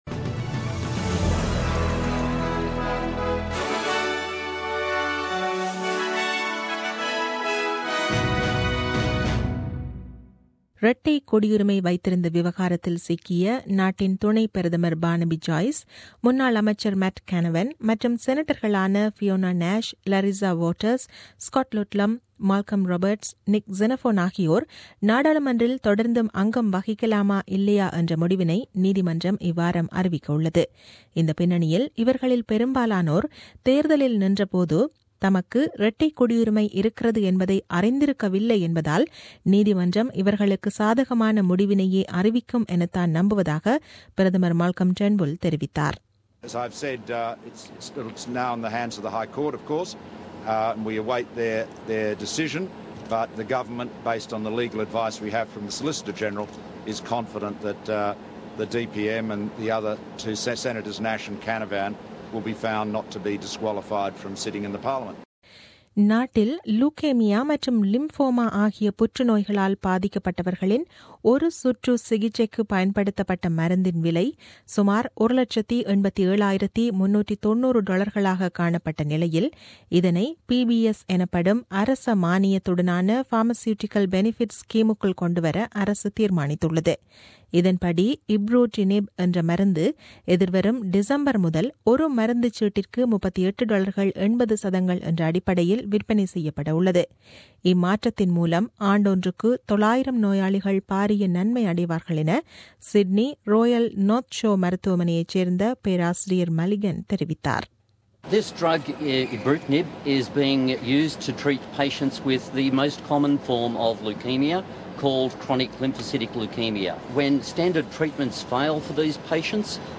The news bulletin aired on 09 October 2017 at 8pm.